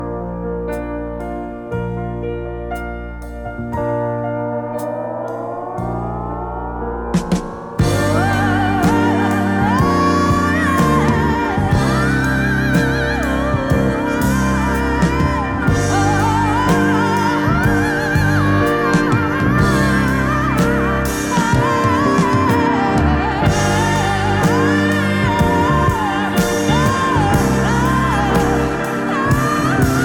Ens.voc & instr.